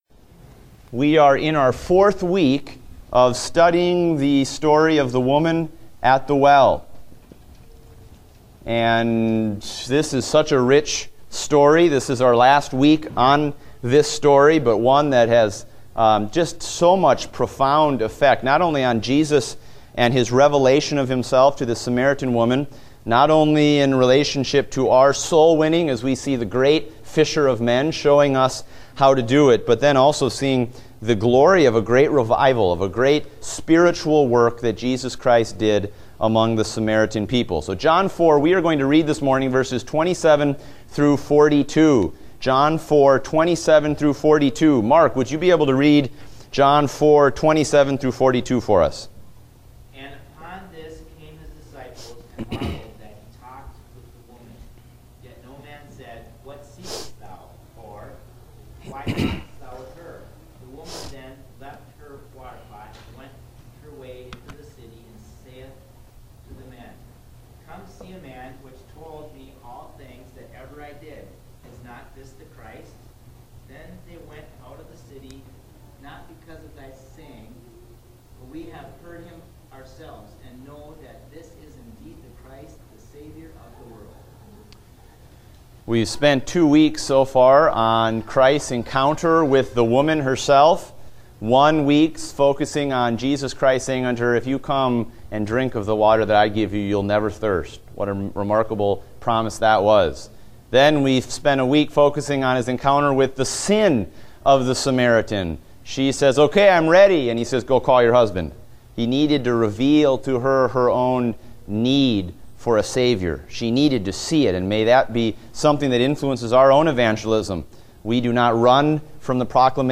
Date: July 3, 2016 (Adult Sunday School)